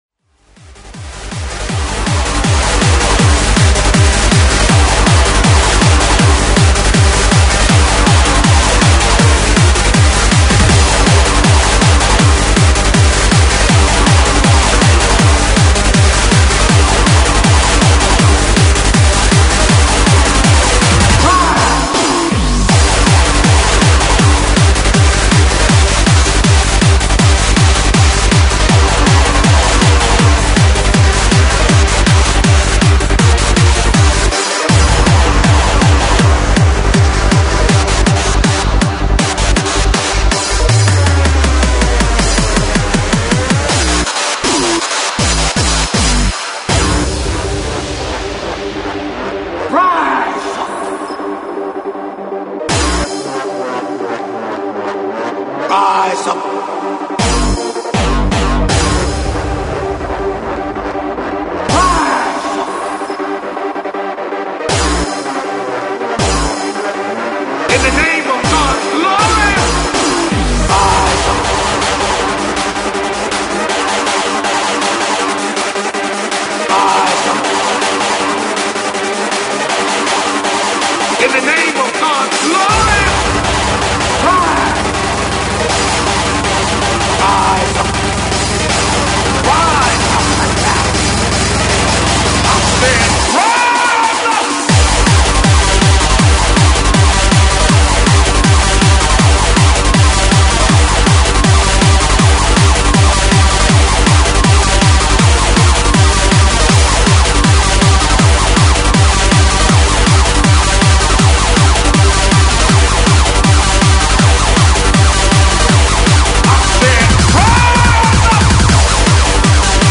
Hard House/Hard Trance/Freeform